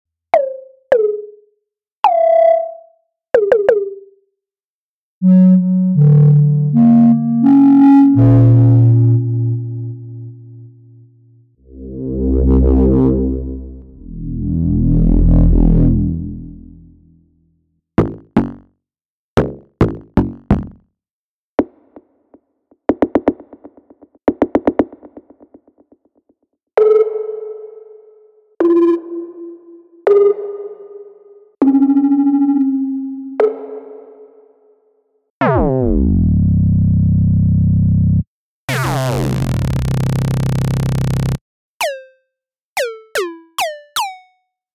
Elektron Gear Model:Cycles
Percussion
Toms
FX